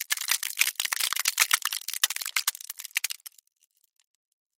Звуки льда
Треск льда при образовании трещин